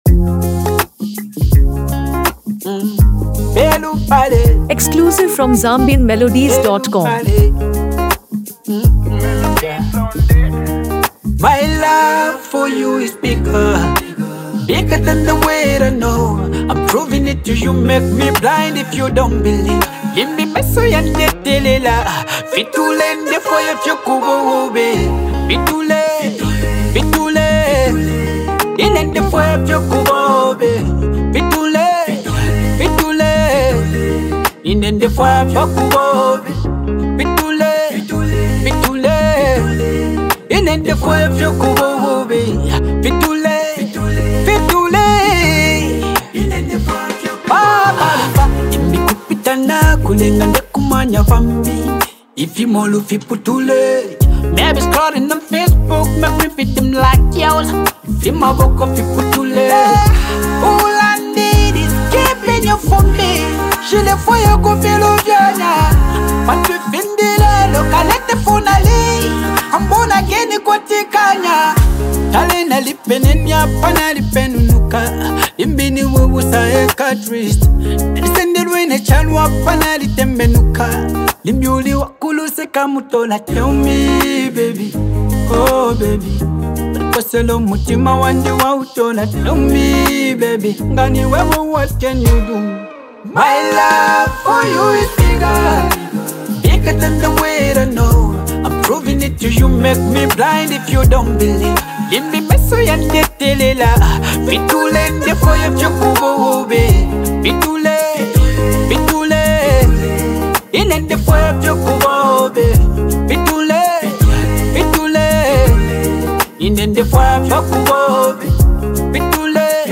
A Soulful Love Song
is a touching love ballad
smooth instrumentals
emotional delivery
praised for its lyrical depth and soothing vibe.